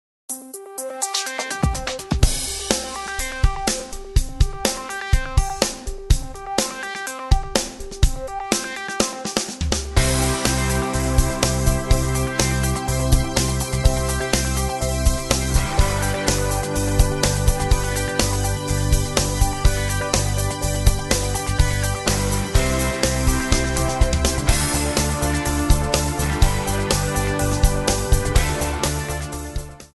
C/C#
MPEG 1 Layer 3 (Stereo)
Backing track Karaoke
Pop, 1970s